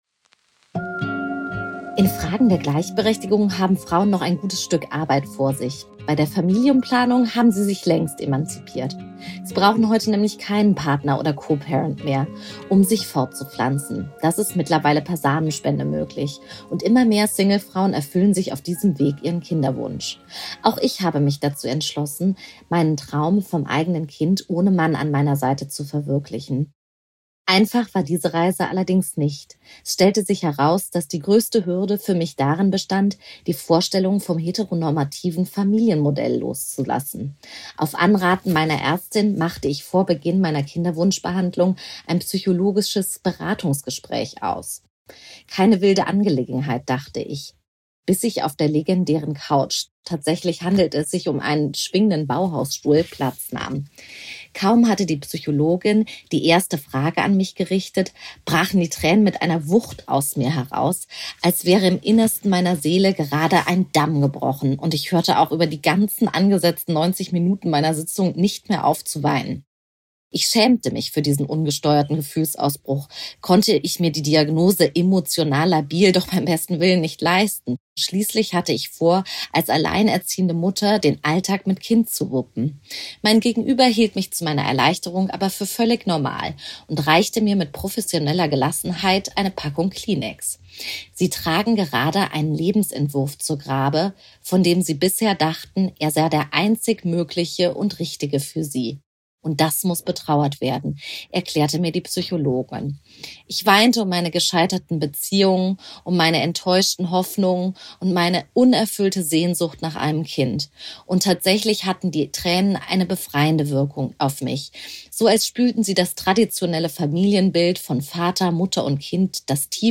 Audioartikel